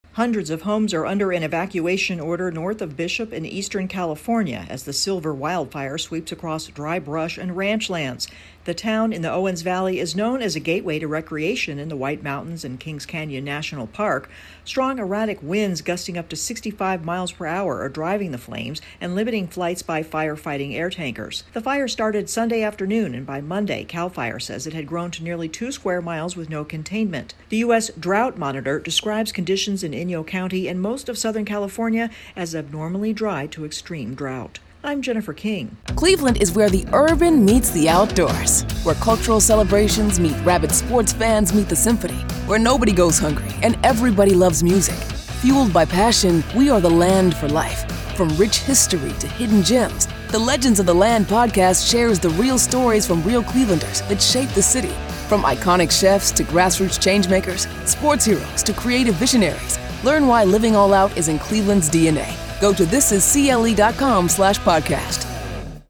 High winds are fanning a wildfire east of the Sierra Nevada mountains. AP correspondent